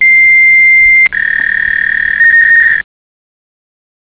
Cŕŕn Zvuk klasickeho stareho modemu. 0:10